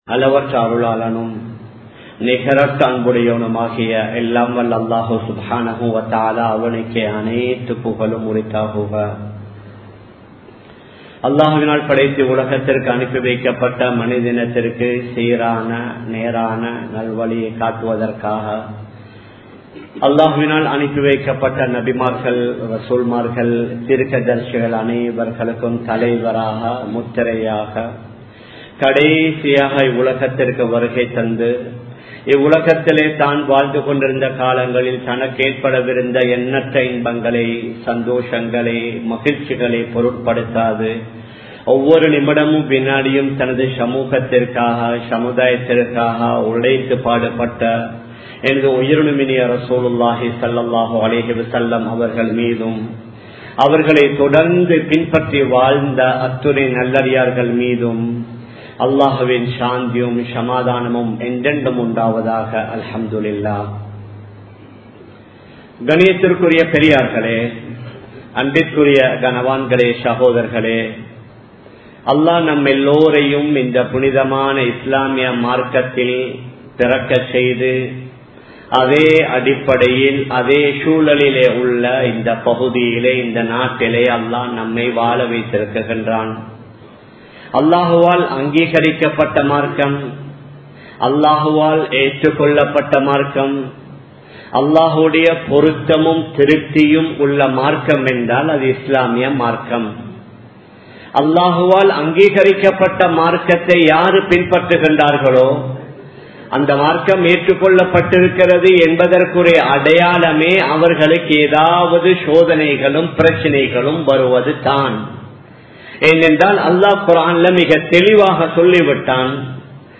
Maattru Mathaththavarhaludan Nalla Muraiel Palahungal (மாற்று மதத்தவர்களுடன் நல்ல முறையில் பழகுங்கள்) | Audio Bayans | All Ceylon Muslim Youth Community | Addalaichenai
Colombo 11, Samman Kottu Jumua Masjith (Red Masjith)